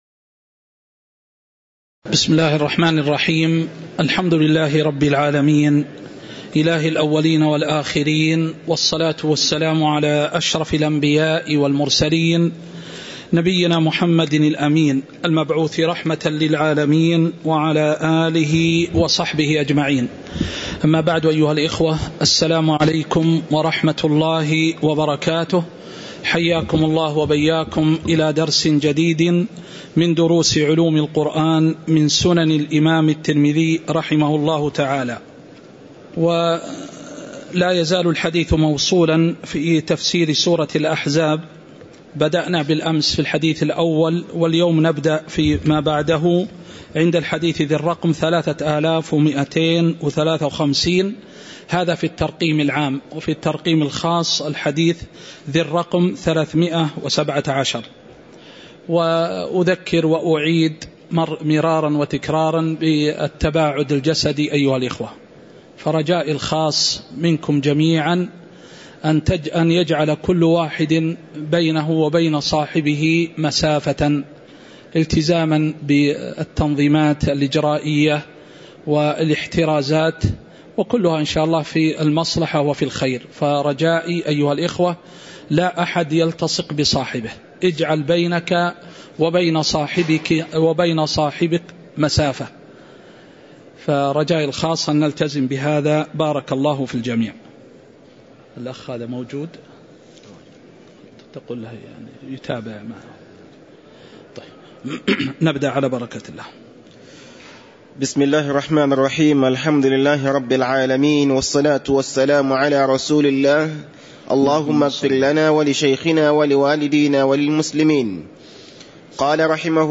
تاريخ النشر ١٤ جمادى الآخرة ١٤٤٣ هـ المكان: المسجد النبوي الشيخ